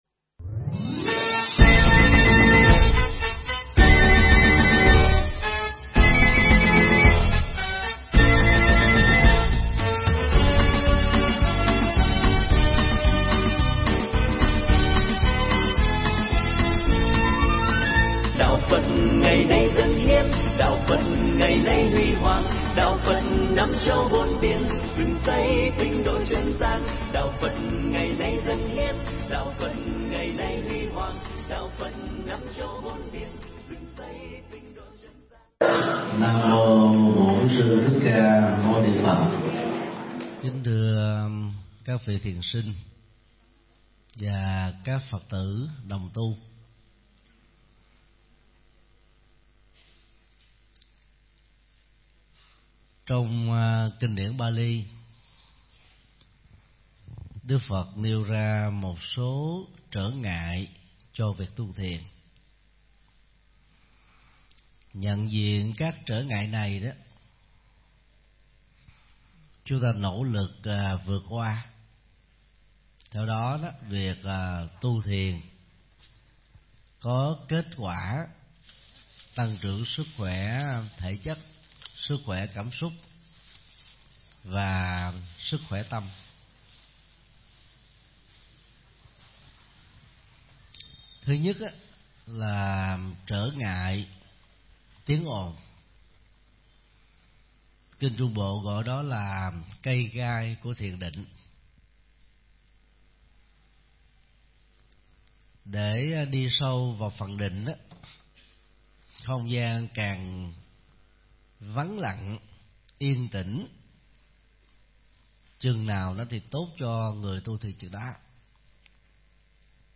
Vấn Đáp: Quản Trị Thời Gian Để Tập Thiền, Thiền Chỉ Và Thiền Quán
giảng trong khóa tu thiền Tứ Niệm Xứ tại chùa Giác Ngộ